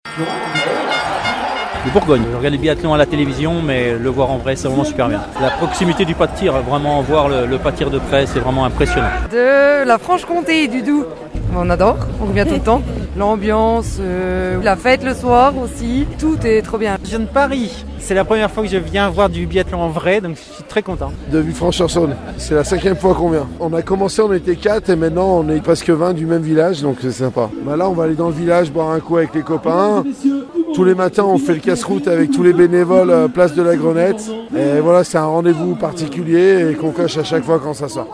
Pour la première épreuve, le sprint homme, le public a été fidèle a sa réputation et a assuré l'ambiance.
Certains spectateurs n'ont pas hésité à faire plusieurs centaines de kilomètres pour être présent.